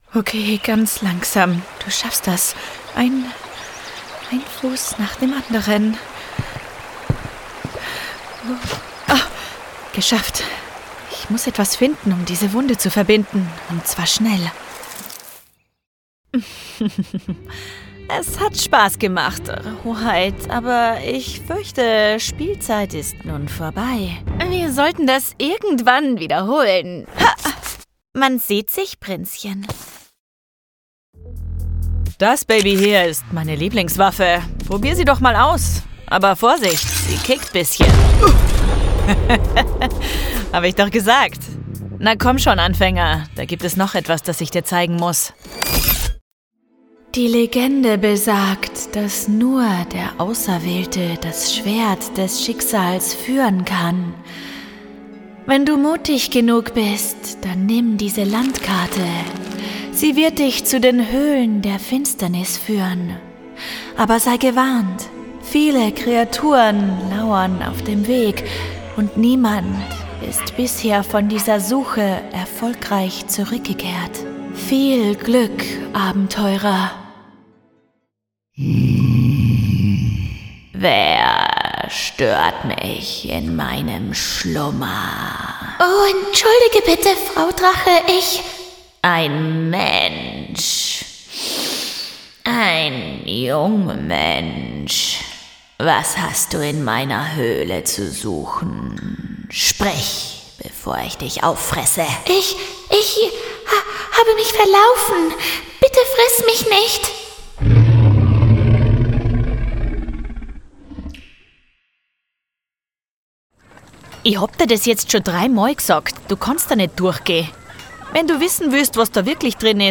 Kommerziell, Junge, Vielseitig, Freundlich
Persönlichkeiten